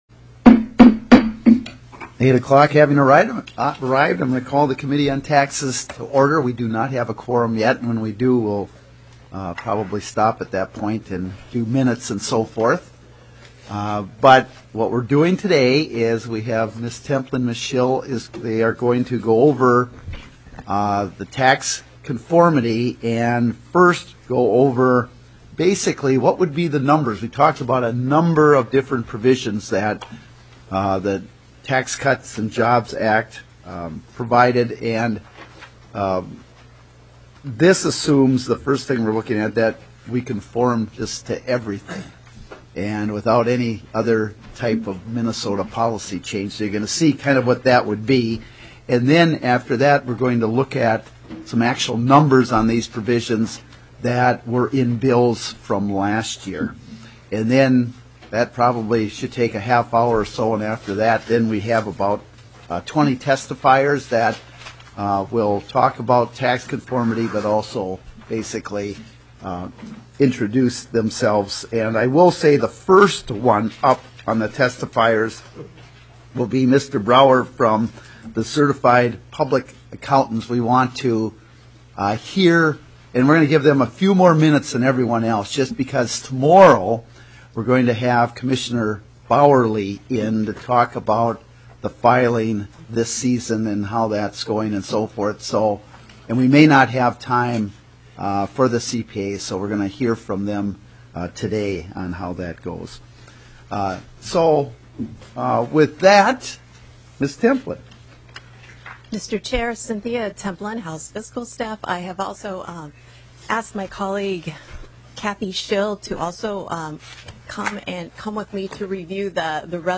Tax conformity overview by House Research and House Fiscal Analysis staff. 36:29 - Public testimony on tax conformity.